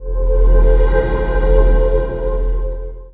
Magic_SpellCloak01.wav